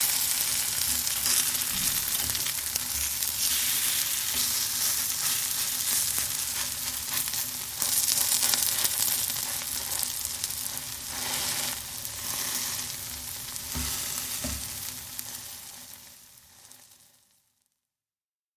Звуки картошки
Шум жареной картошки на сковородке